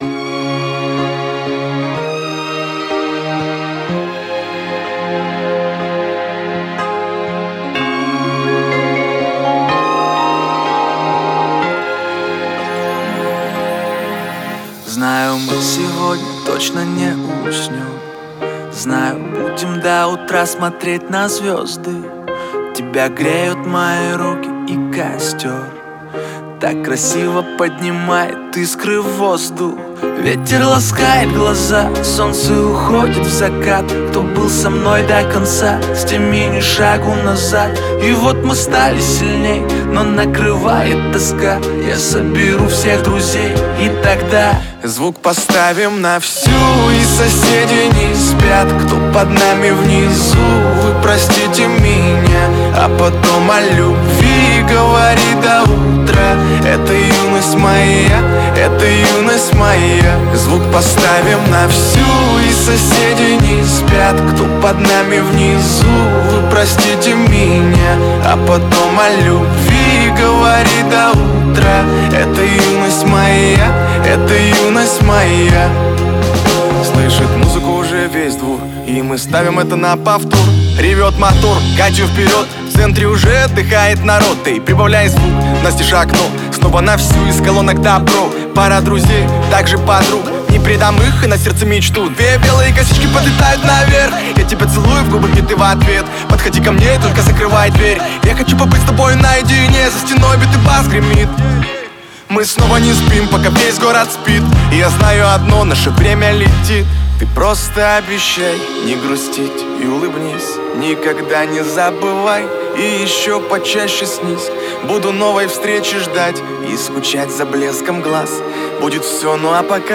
это яркий и вдохновляющий трек в жанре поп